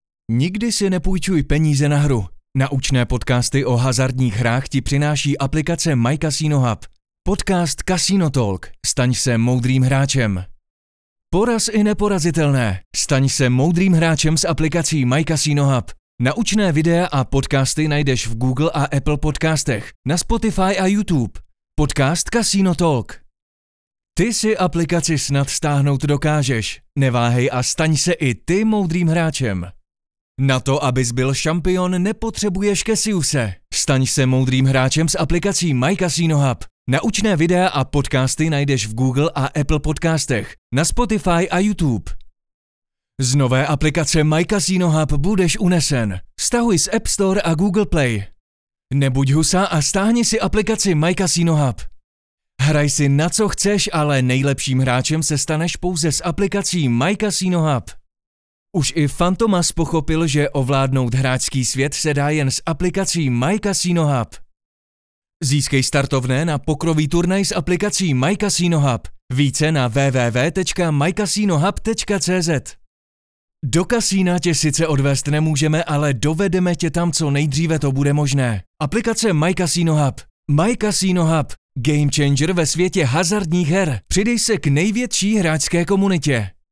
Obdržíte kvalitní zvukovou stopu - voiceover (wav/mp3), vyčištěnou od nádechů a rušivých zvuků, nachystanou pro synchronizaci s vaším videem.
Mužský voiceover do Vašeho videa (Voiceover / 90 sekund)